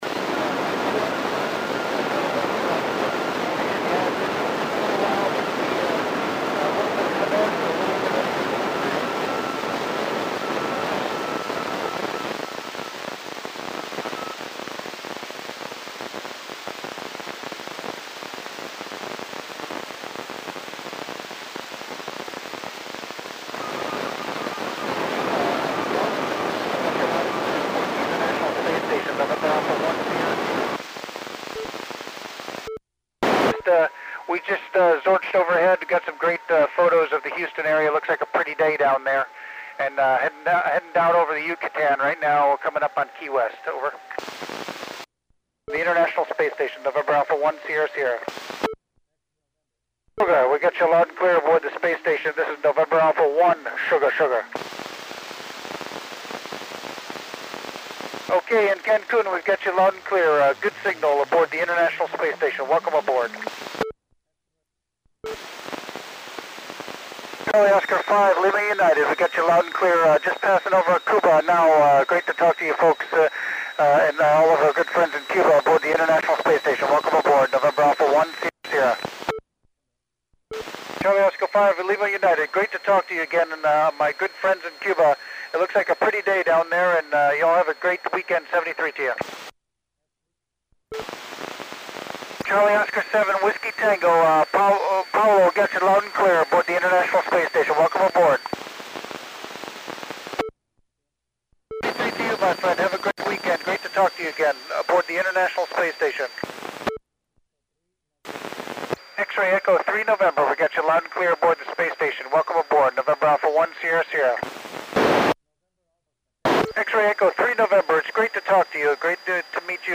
NA1SS (Col. Doug Wheelock) working Cuban and Mexican Stations on 03 October 2010 at 1754 UTC
Very clear and strong from my side.
NA1SS (Col Doug Wheelock) calling with Cuban and Mexican Stations